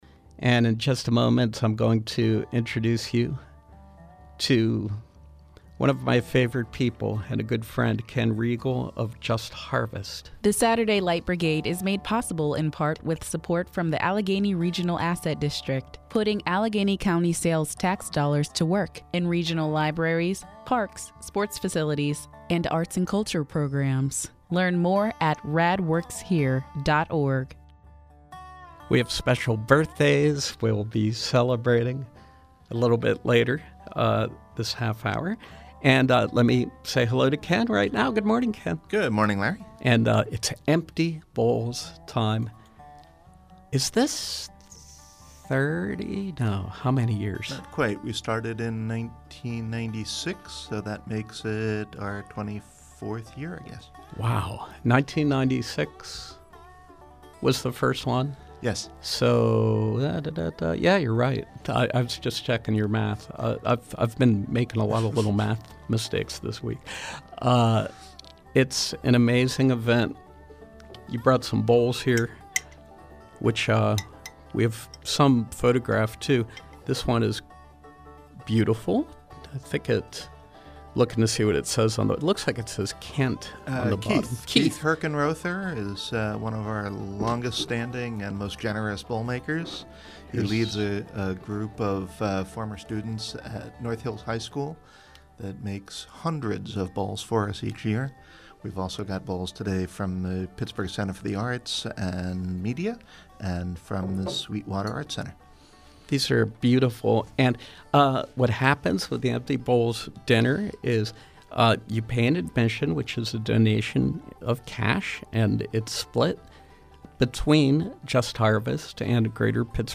In Studio Pop-Up: Just Harvest
Interviews